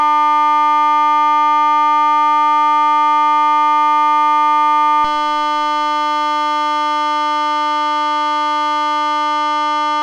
The 300Hz difference tone is audible when distortion is added, but I think the new recording shows the results more clearly, using 1,100Hz and 900Hz.
It's only 10s duration, with 5s for each form of distortion.